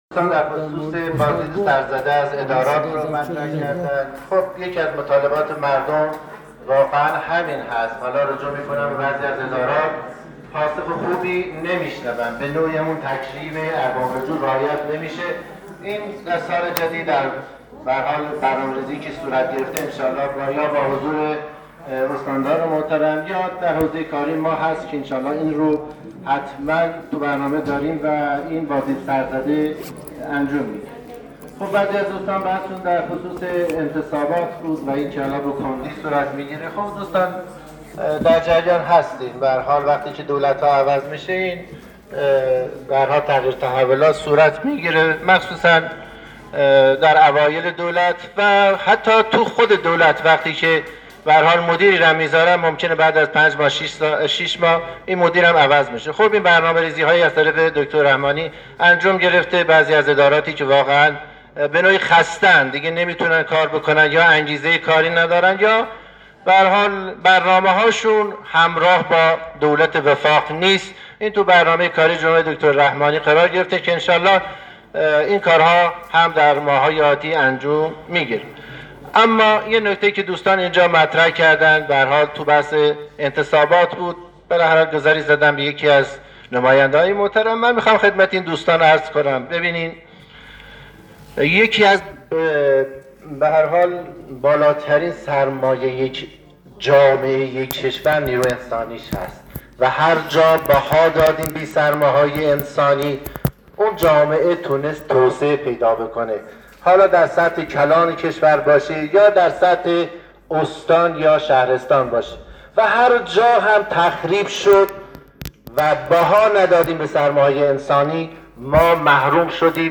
معاون استاندار کهگیلویه و بویراحمد در نشست خبری یدالله رحمانی، استاندار کهگیلویه و بویراحمد با رسانه‌‌ها تأکید کرد که برنامه‌ریزی‌های جدید برای بازدیدهای سرزده از ادارات و تغییرات مدیریتی در دستور کار قرار گرفته است. وی همچنین از رسانه‌ها خواست از تخریب نخبگان و مدیران خودداری کنند و در کنار انتقاد سازنده، به توسعه استان کمک کنند.